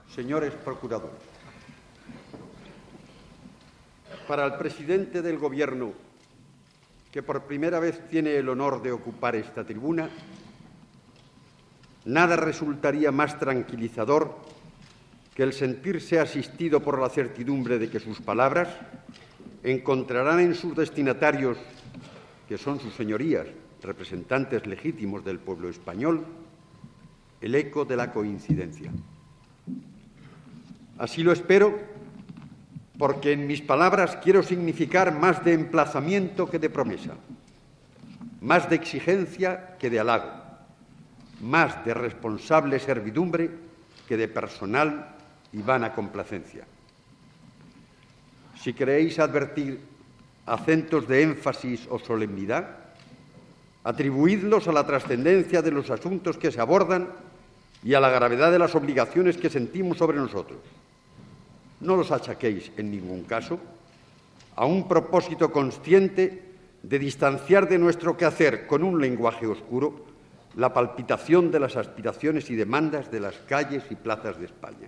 Discurs del president del govern espanyol Carlos Arias Navarro als Procuradores en Cortes
Informatiu
Extret del programa "El sonido de la historia", emès per Radio 5 Todo Noticias el 16 de febrer de 2013